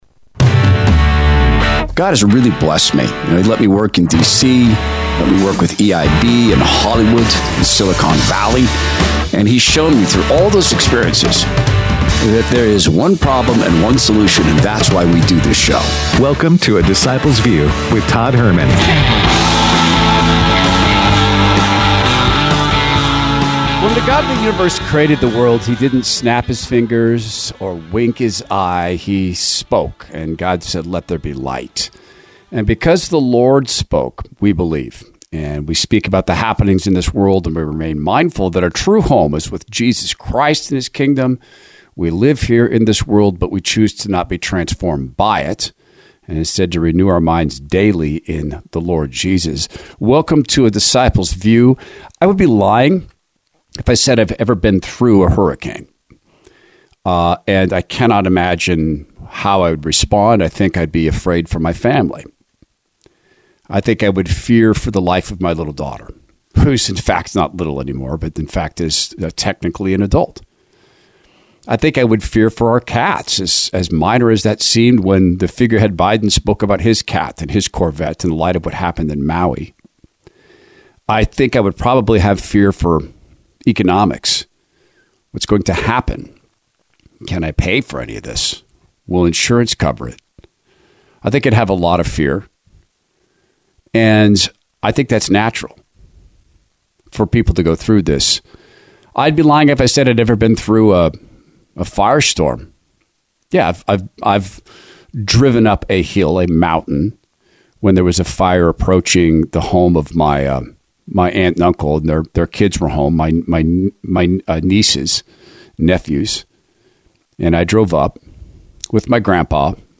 Tucker Carlson's Interview With Hungary's Prime Minister Orban